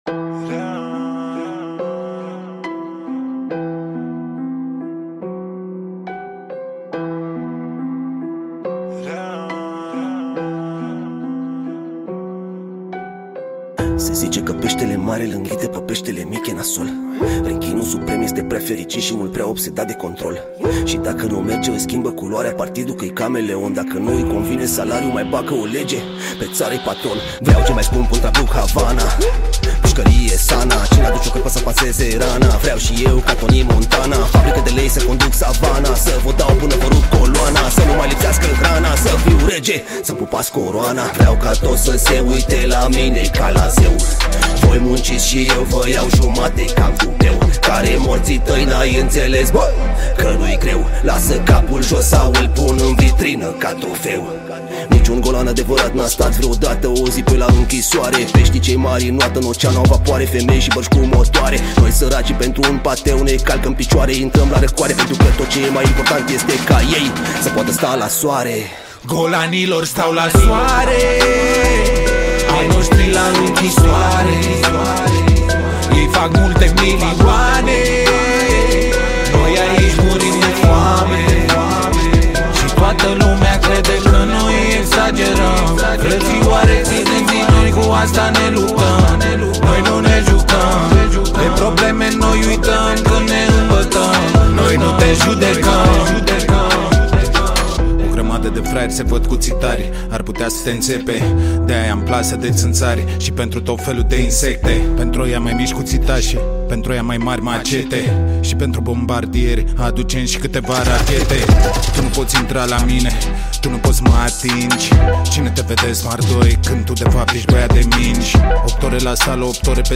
Data: 26.10.2024  Hip Hop New Hits: 0